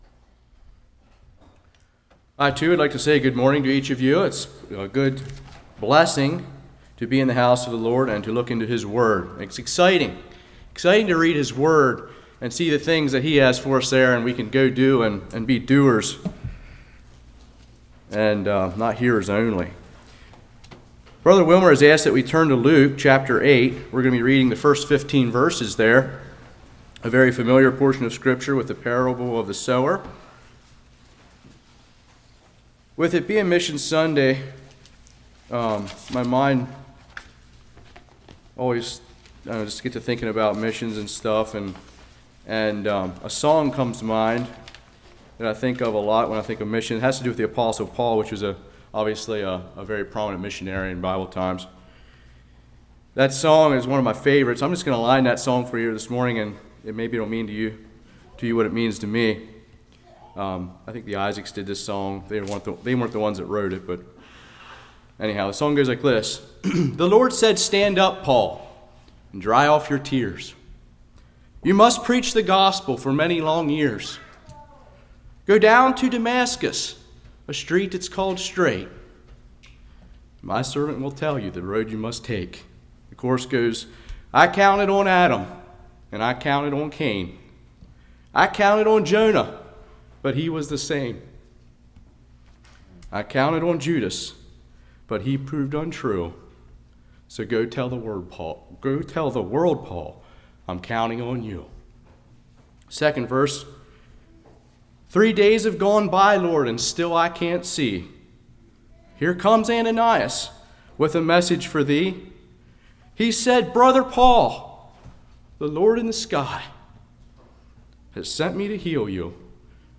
Luke 8:1-15 Service Type: Morning Who is the Sower Satan uses doubt to take away the seed.